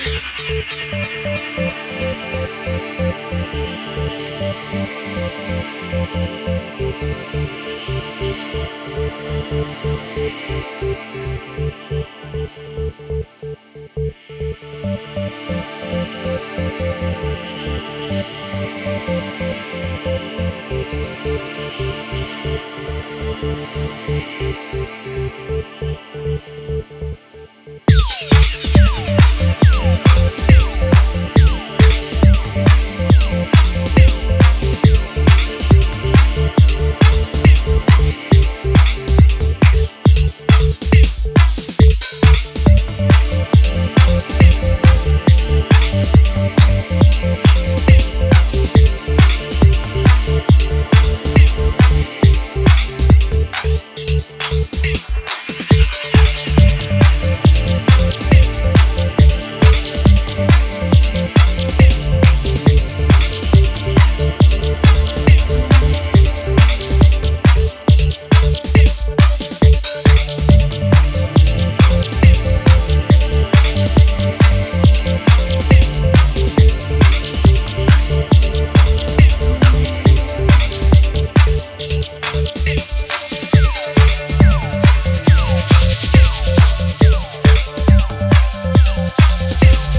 feels like a never ending trance